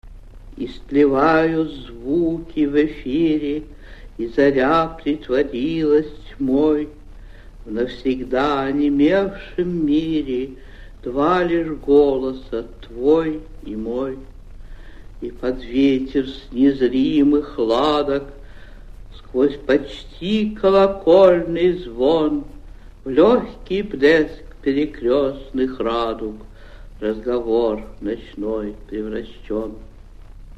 2. «Анна Ахматова – CINQUE – Истлевают звуки в эфире (читает автор)» /